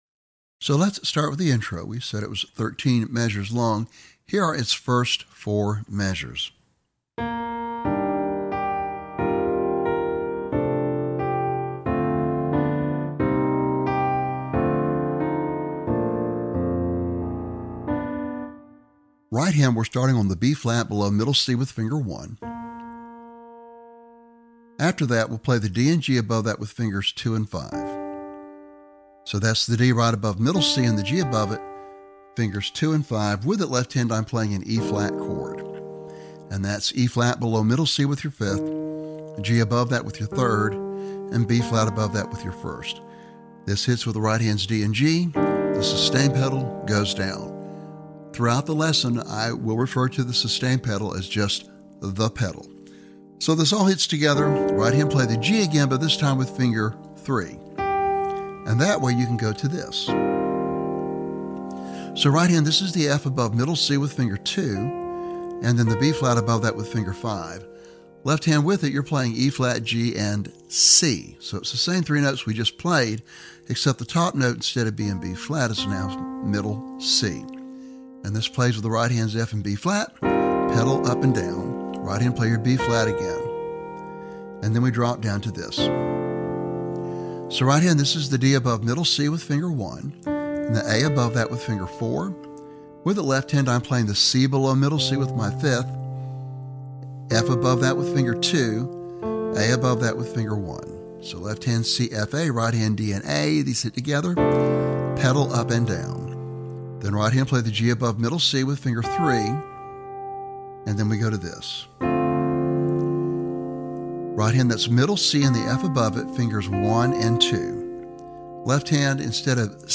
Lesson Sample  Download
jazzy, intermediate level piano solo